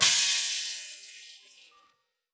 SMANS_Snare_FX.wav